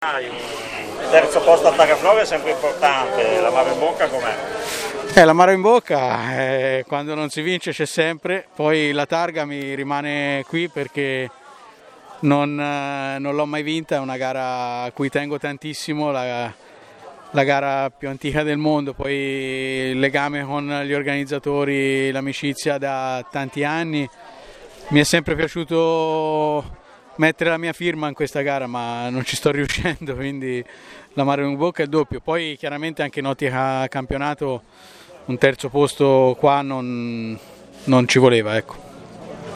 Interviste Rally Targa Florio 2020
Interviste di fine rally